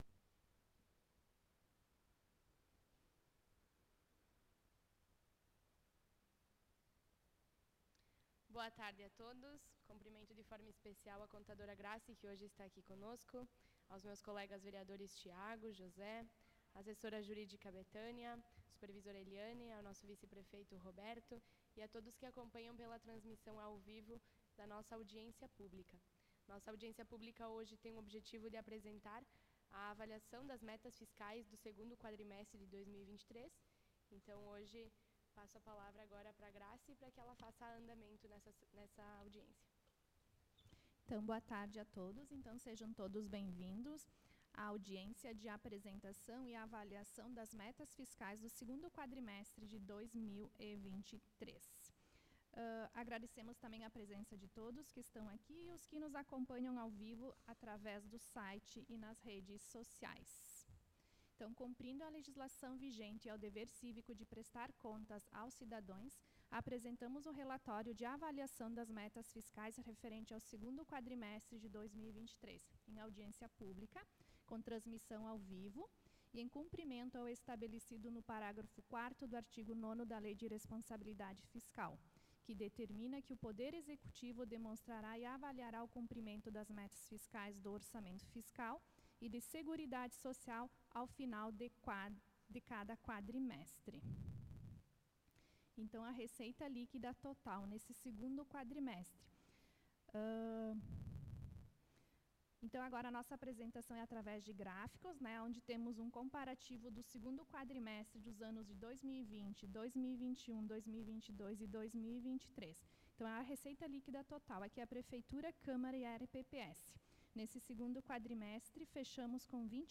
Audiência Pública
Câmara de Vereadores de Nova Roma do Sul